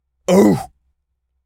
Animal_Impersonations
seal_walrus_hurt_02.wav